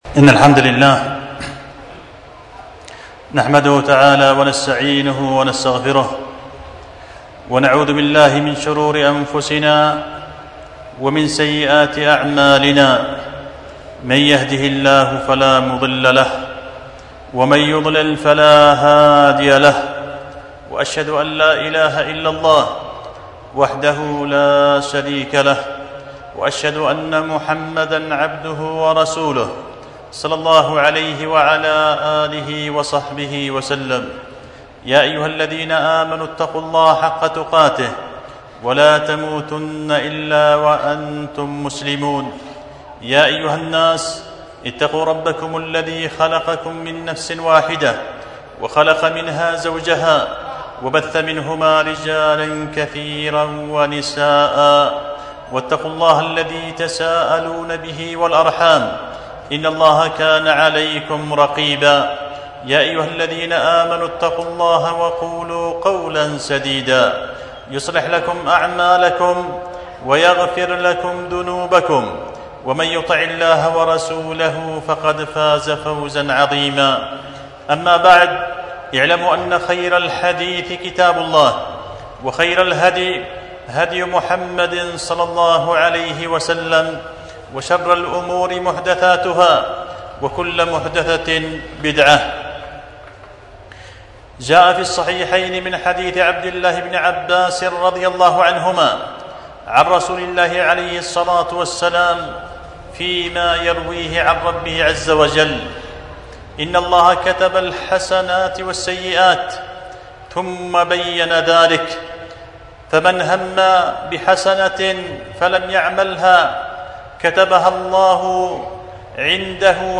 خطبة جمعة بعنوان ضياء السالك في بيان أنه لا يهلك على الله إلا هالك